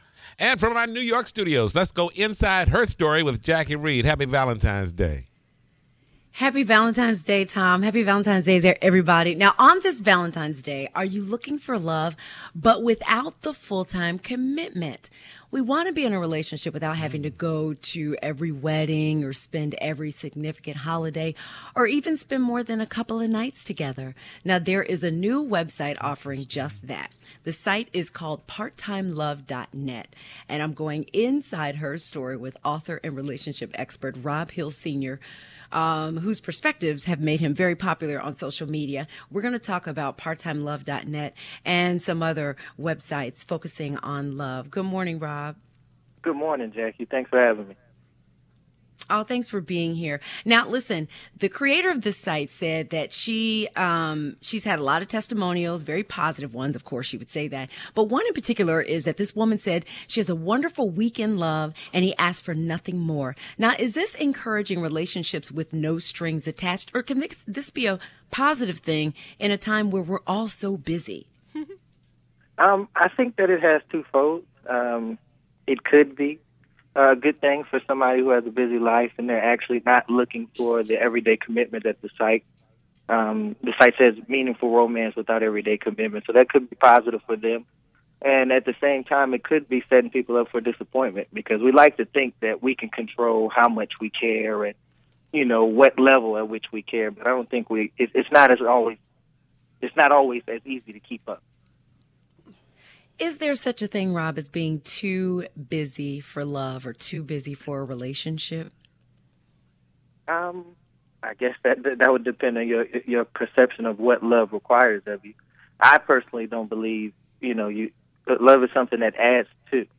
Jacque Reid goes “Inside Her Story” with author and relationship expert